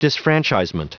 Prononciation du mot disfranchisement en anglais (fichier audio)
Prononciation du mot : disfranchisement